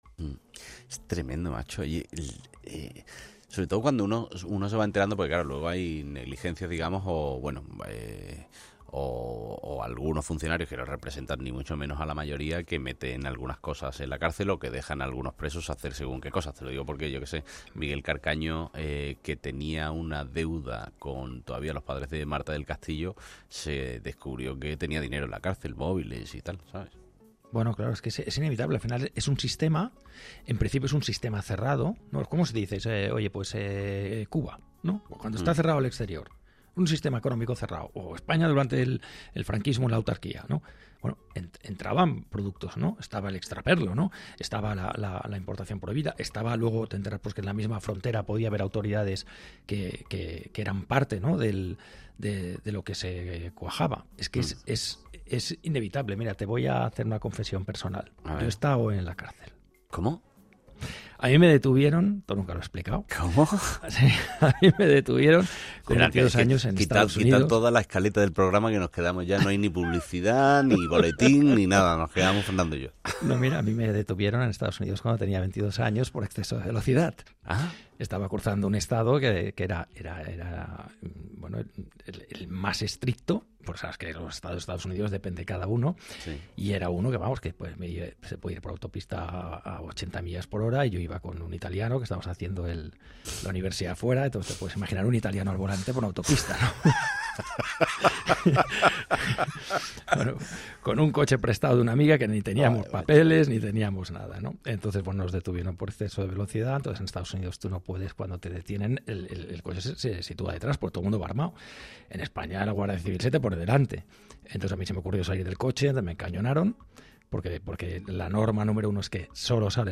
El economista Fernando Trías de Bes explica cómo pagó una vez una fianza con traveler's check